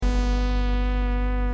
SONS ET SAMPLES DU SYNTHÉTISEUR OBERHEIM MATRIX 1000